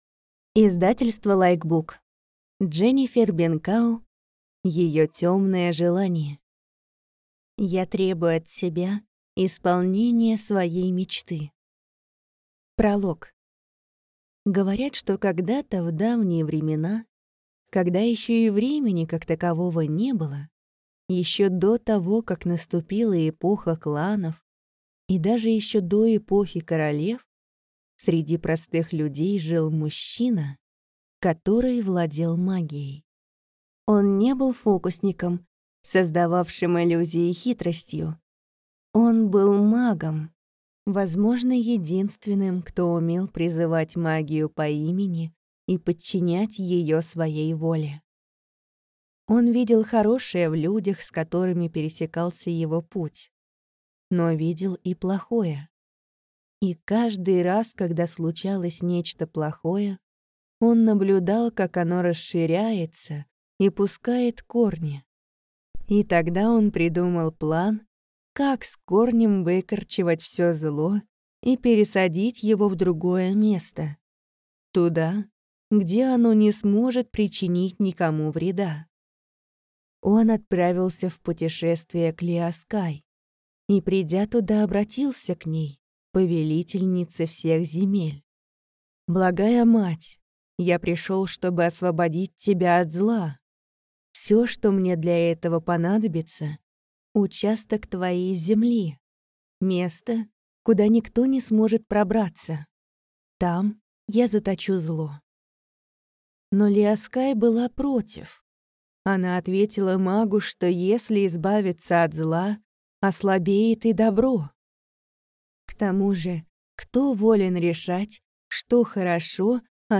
Аудиокнига Ее темное желание. Царство теней. Книга 1 | Библиотека аудиокниг